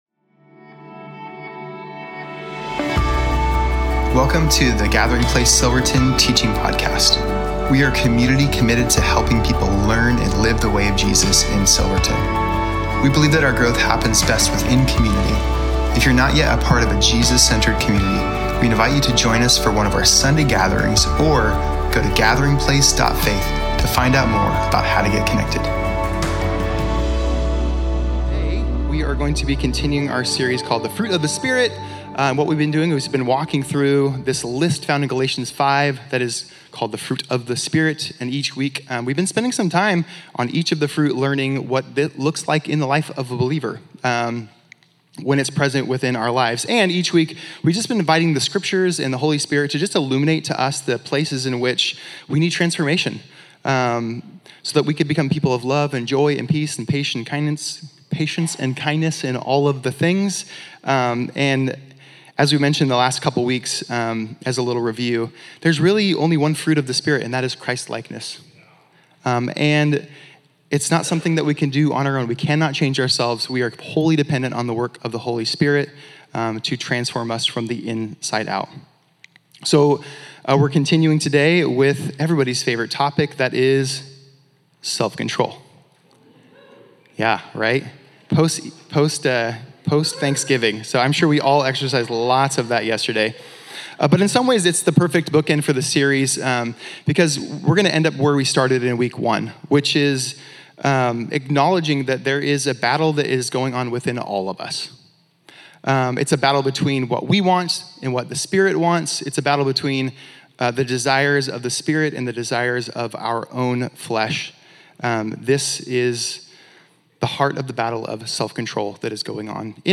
Home About Connect Events Sermons Give The Fruit of The Spirit-Part 9-Self-Control November 30, 2025